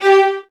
VIOLINS.AN-L.wav